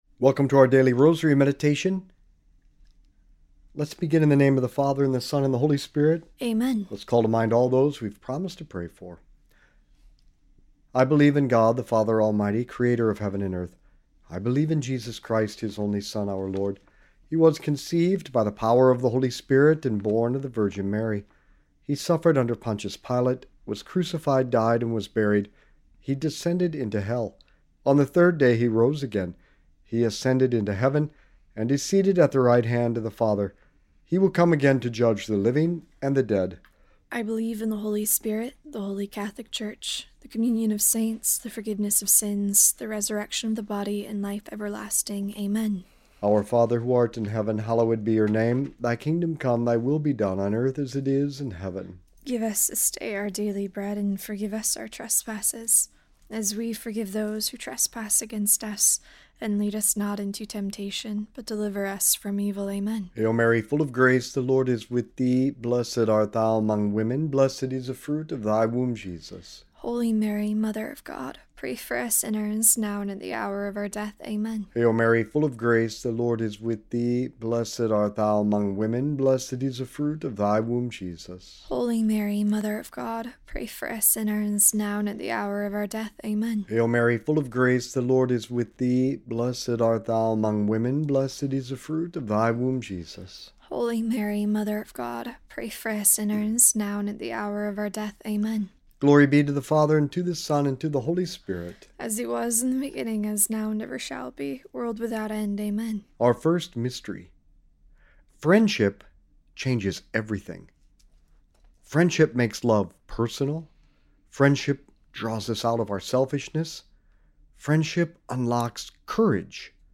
This episode is a Catholic daily rosary meditation that explores the theme of friendship as the foundation for courage and moral goodness.